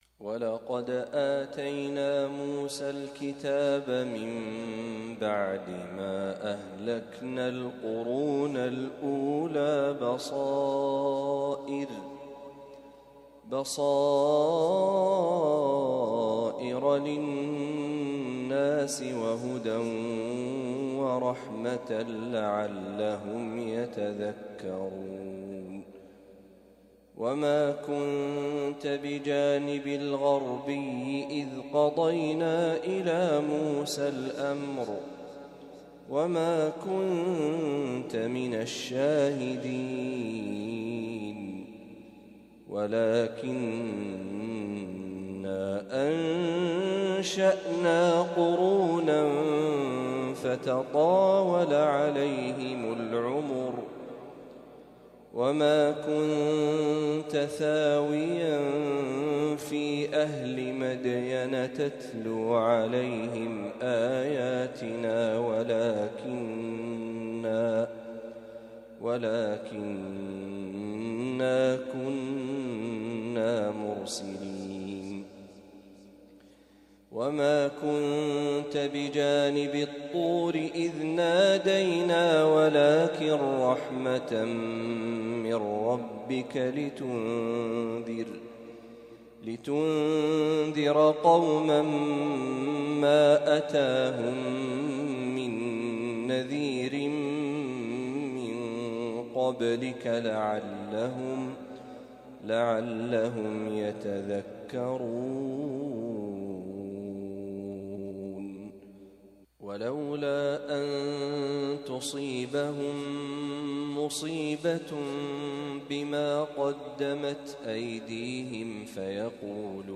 تلاوة لما تيسر من سورة القصص | فجر الجمعة ٢٩ ذو الحجة ١٤٤٥هـ > 1445هـ > تلاوات الشيخ محمد برهجي > المزيد - تلاوات الحرمين